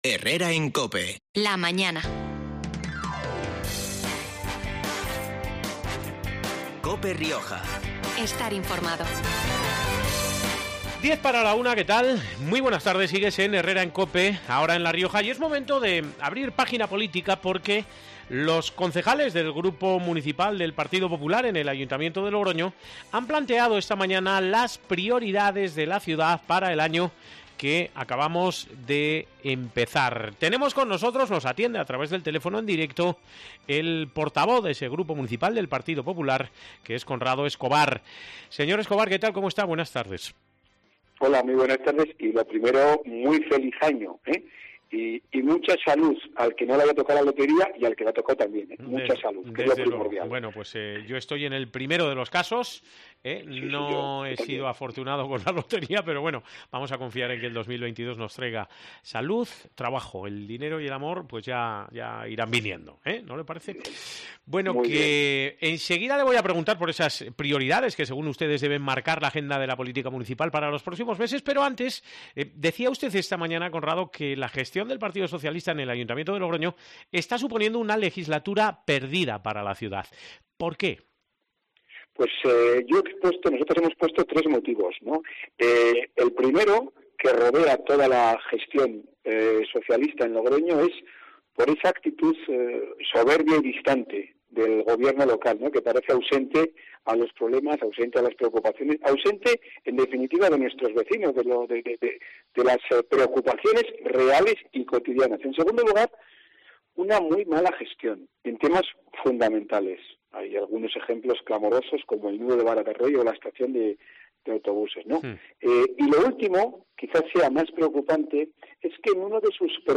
Entrevista en COPE Rioja a Conrado Escobar, portavoz del PP de Logroño